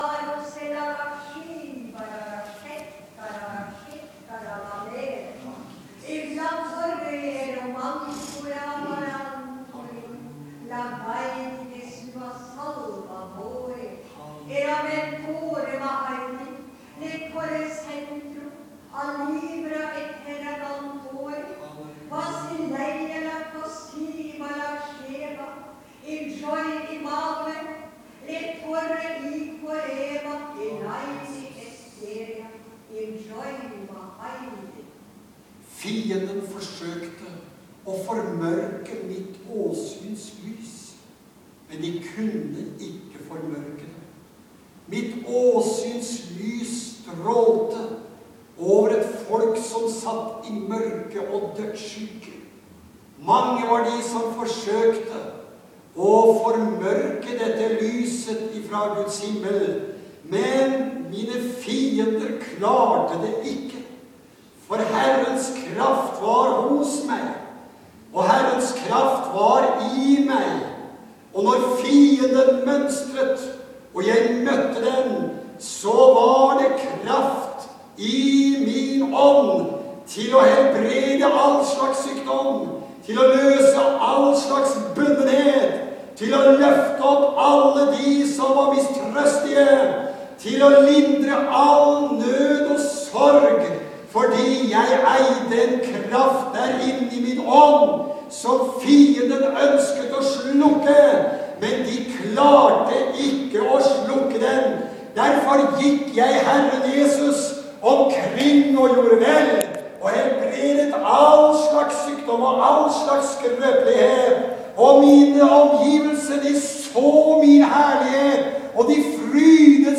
Tungetale